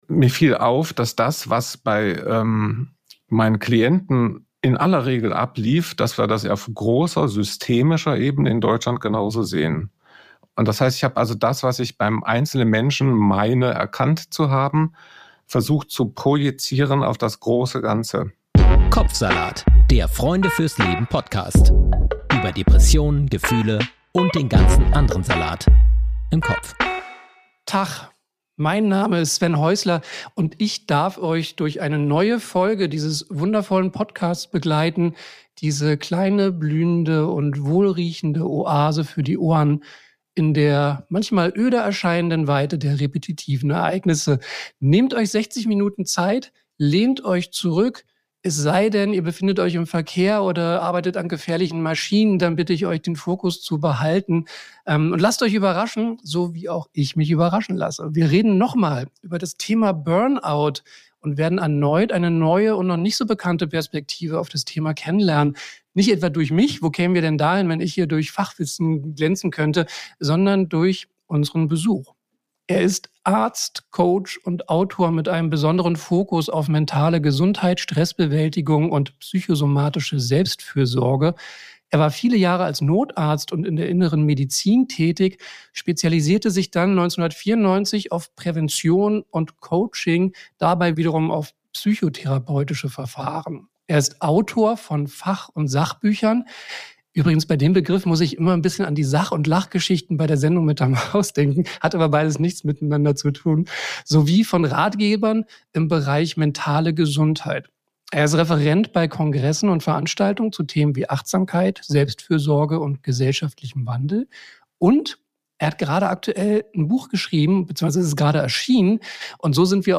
Musik und Jingle